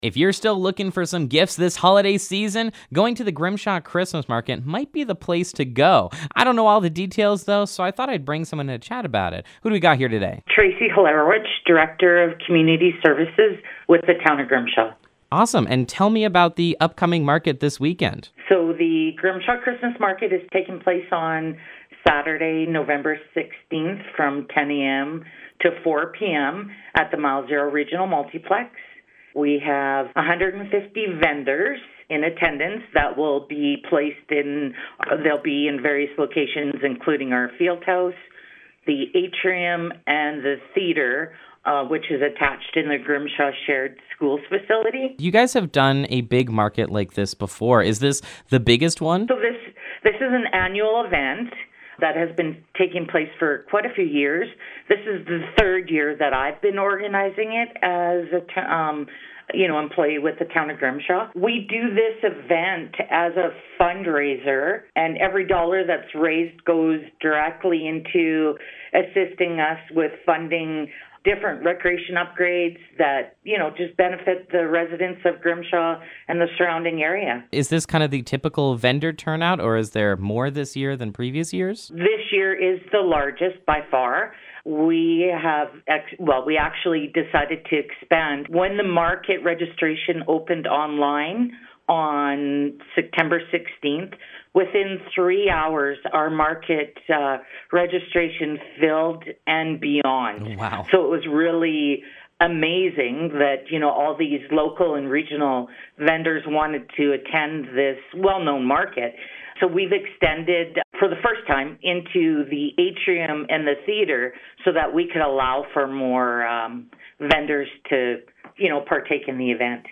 Grimshaw Christmas Market Interview